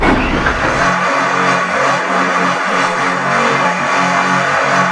saw_start2.wav